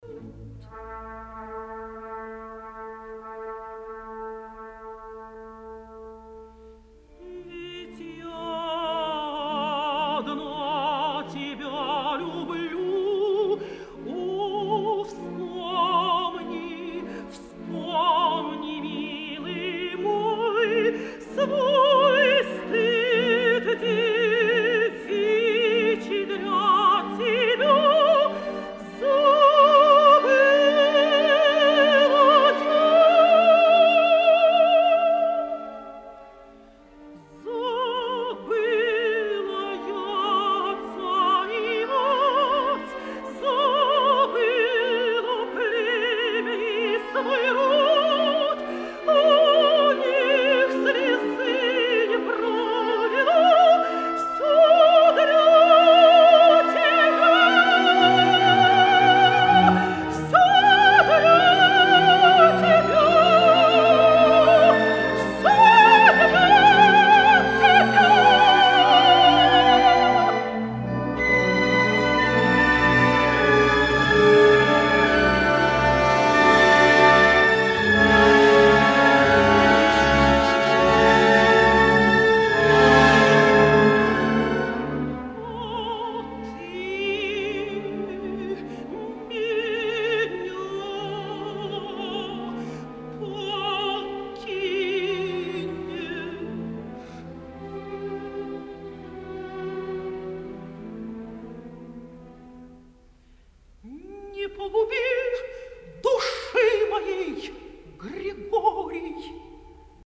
Оркестр Большого театра СССР, Дирижёр Борис Хайкин.